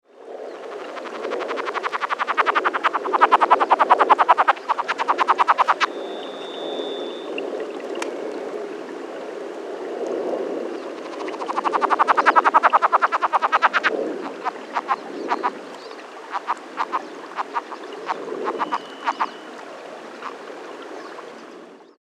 The following sounds were recorded on two sunny afternoons at the end of June and beginning of July at about 3,900 ft in Kittitas County, Washington.
Birds and trickling water are heard in the background.
Sounds This is a :22 second recording of an encounter and attempted amplexus of two frogs, probably males.